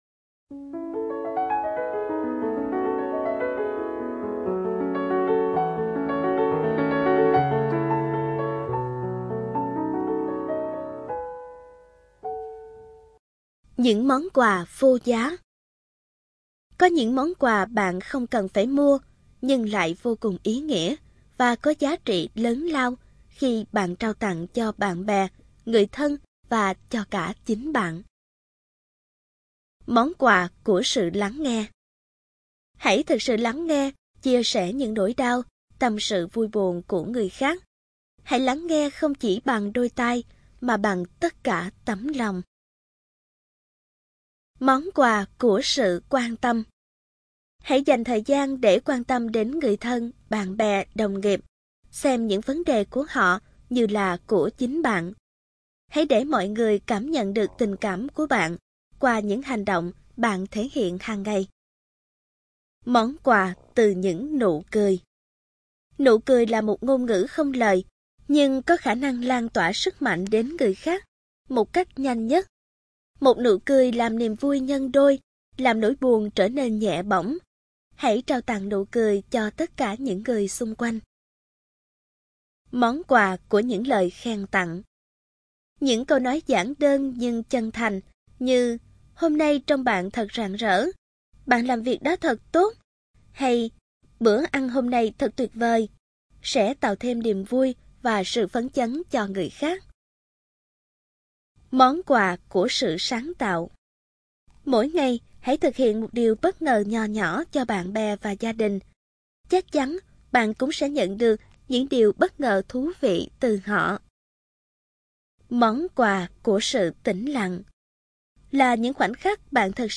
Người đọc